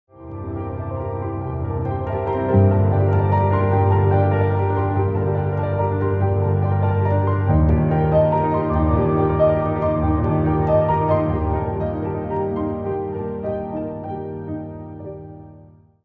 Ducati V4s + Yamaha R1 Sound Effects Free Download